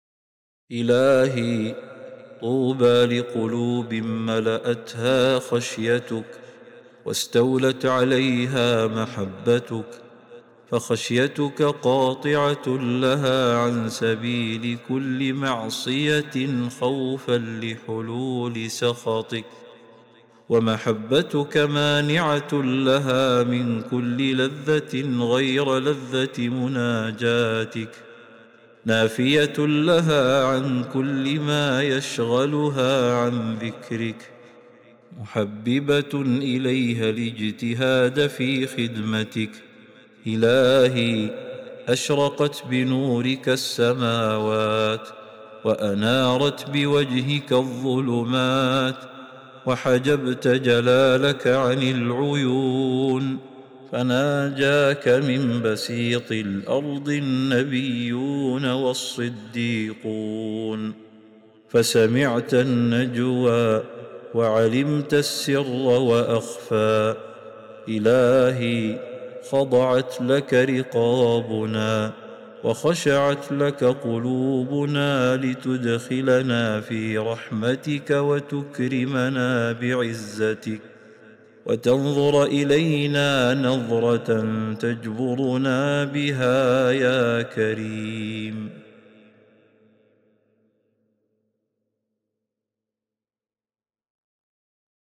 دعاء خاشع يعبر عن امتلاء القلب بمحبة الله وخشيته، ويسأل الله أن يدخله في رحمته ويكرمه بعزته. النص يصور حالة الأنس بالله والانقطاع إليه، مع الاعتراف بجلاله ونوره الذي أشرقت به السماوات.